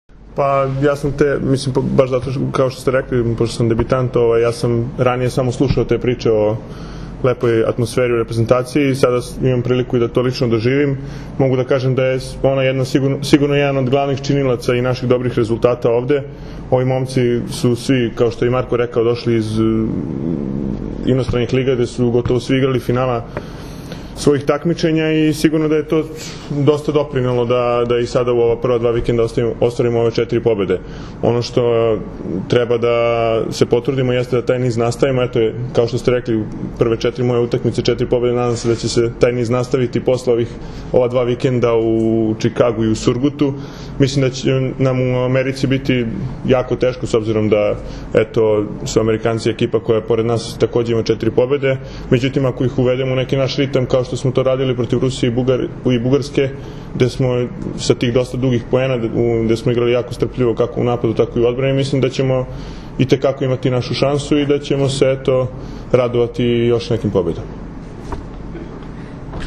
IZJAVA NEVENA MAJSTOROVIĆA